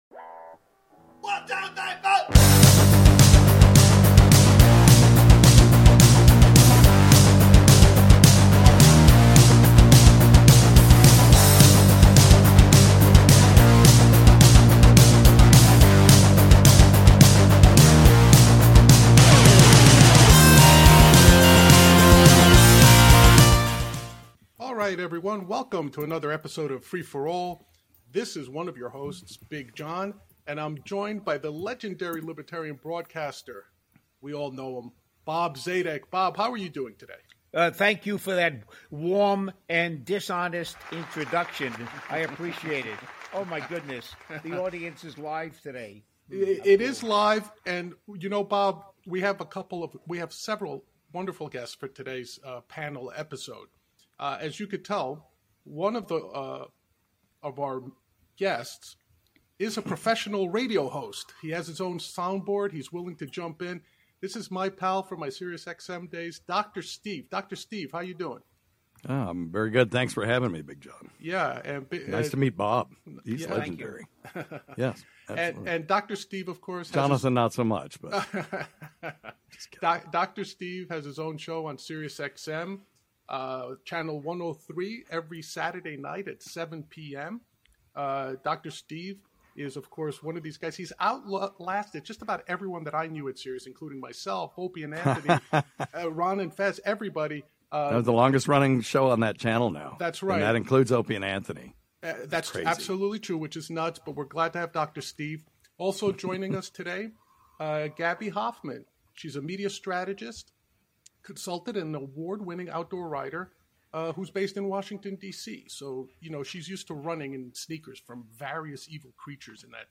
A panel discussion on how the East Palestine train wreck will impact the environment, the role of government, and how individuals can lead in the recovery.